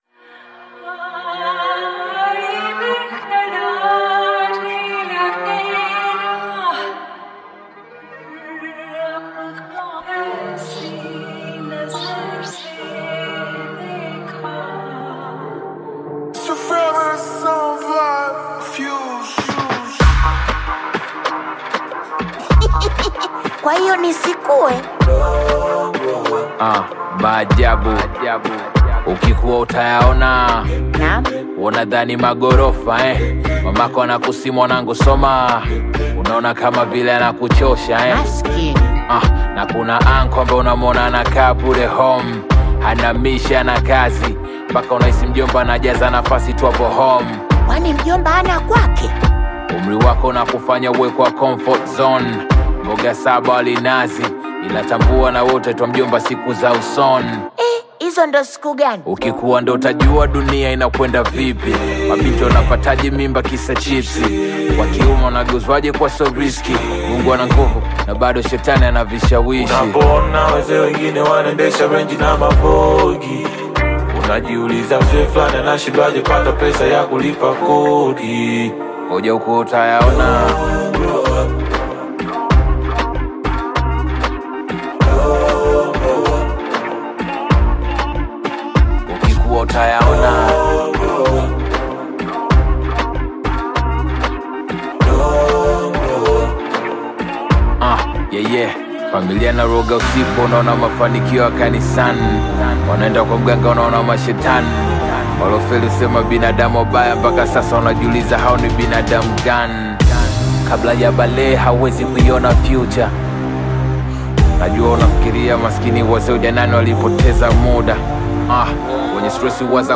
vibrant track
catchy melodies and confident delivery
With its bold sound and engaging performance